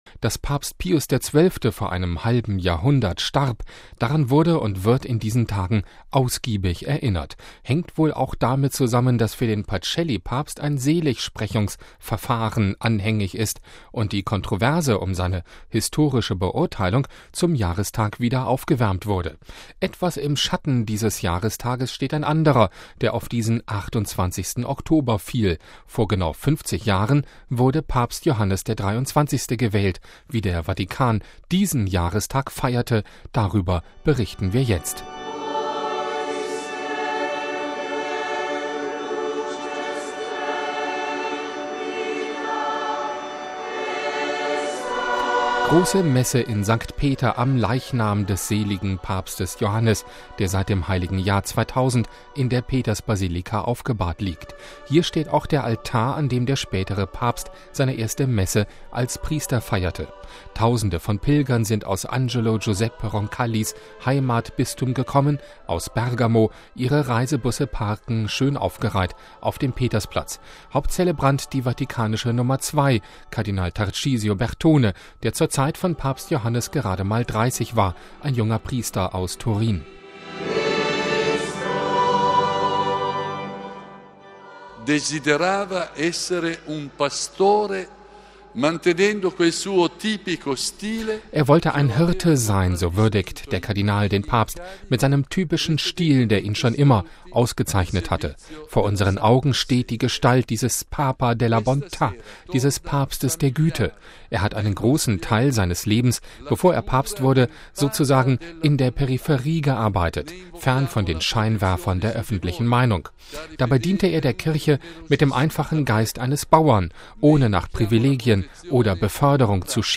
Grosse Messe in St. Peter, am Leichnam des seligen Papstes Johannes, der seit dem Heiligen Jahr 2000 in der Petersbasilika aufgebahrt liegt.